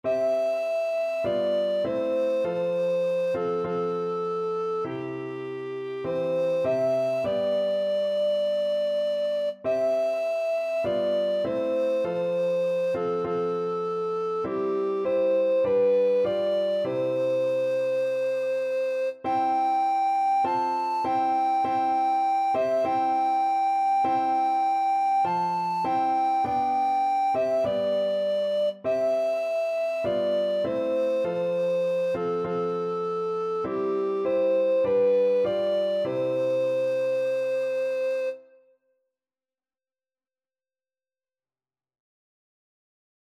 Alto Recorder
C major (Sounding Pitch) (View more C major Music for Alto Recorder )
4/4 (View more 4/4 Music)
Classical (View more Classical Alto Recorder Music)